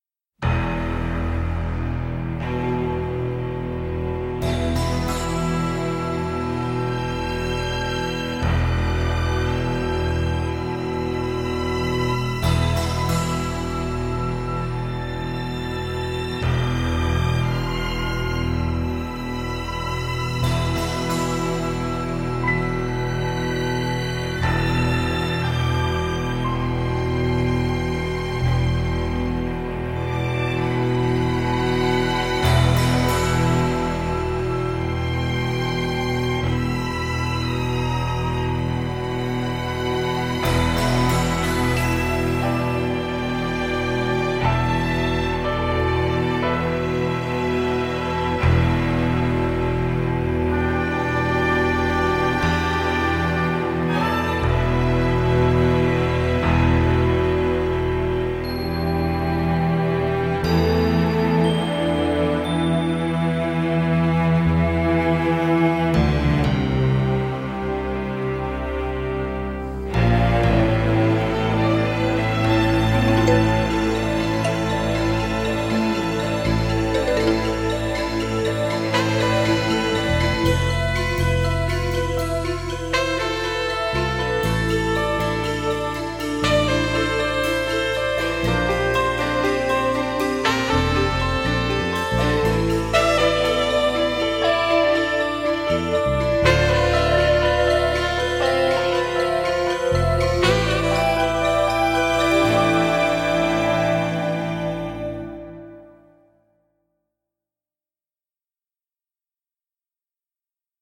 easy listening jazz-folk